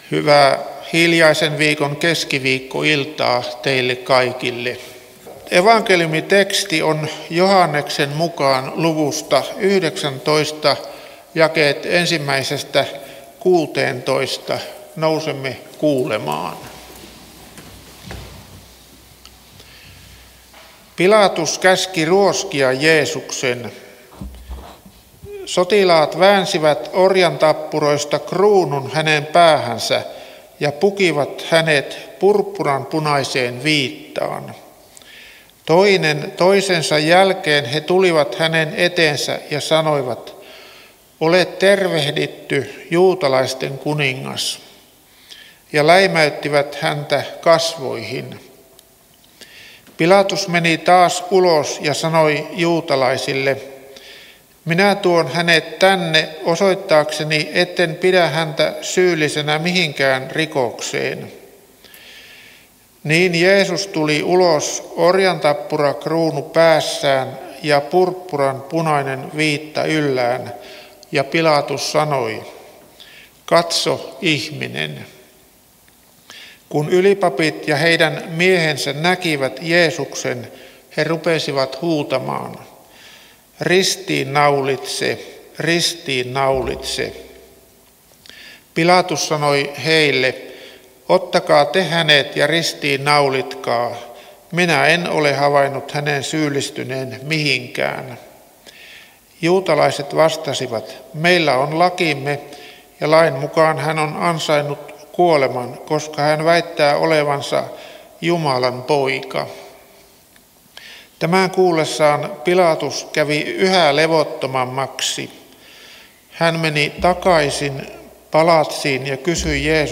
Halsua